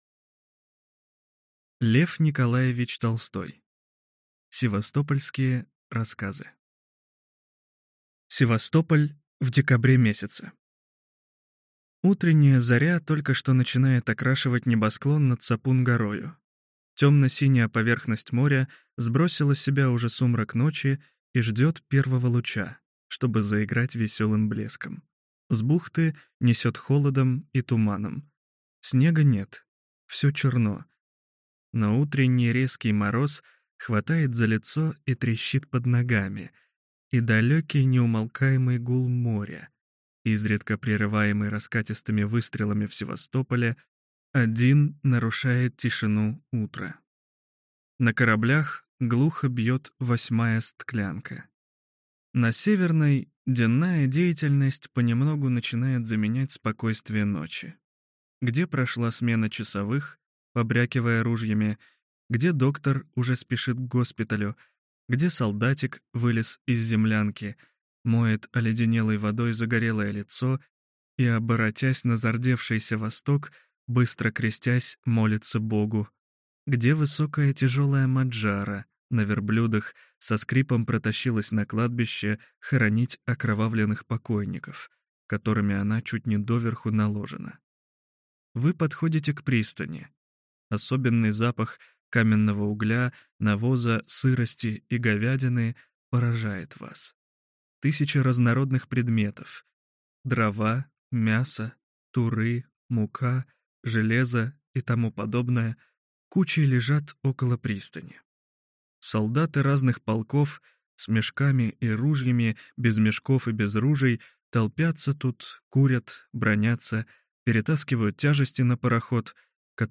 Аудиокнига Севастополь в декабре месяце | Библиотека аудиокниг